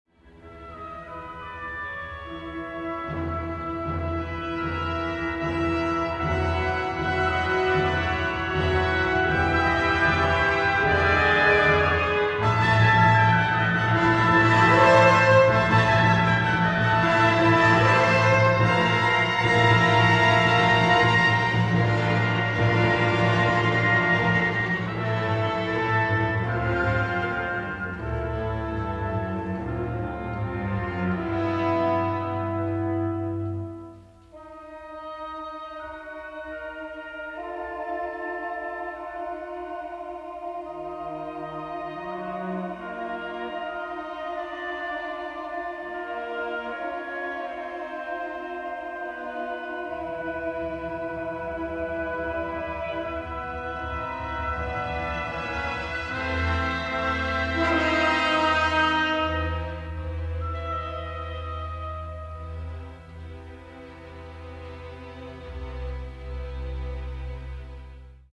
a symphonic orchestral score